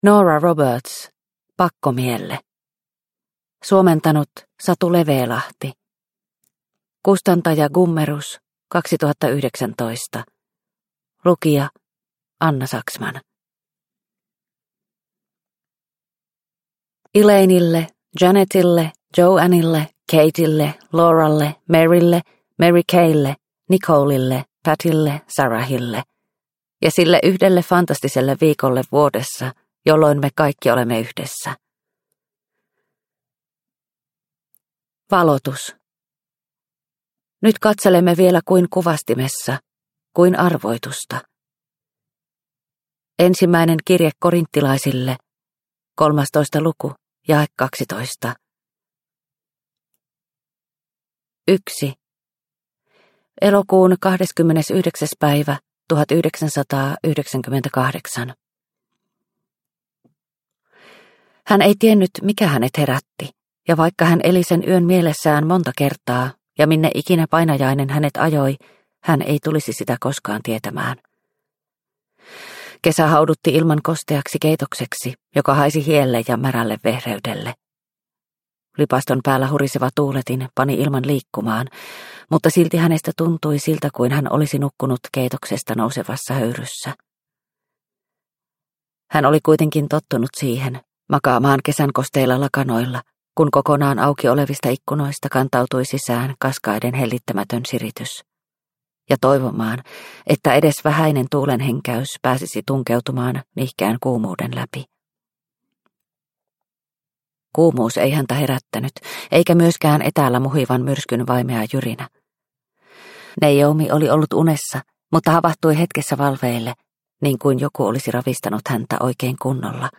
Pakkomielle – Ljudbok – Laddas ner